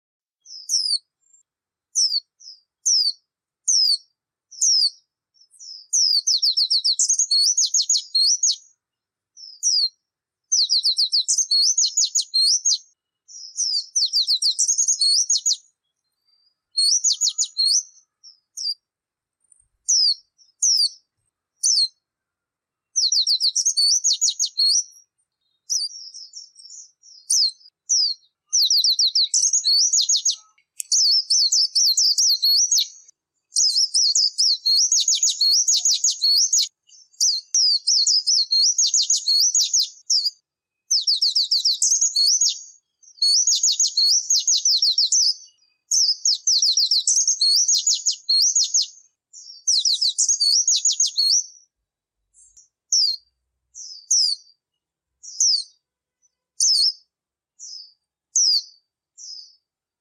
Tiếng Khuyên Líu Hay MP3